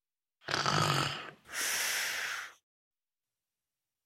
Звук детского храпа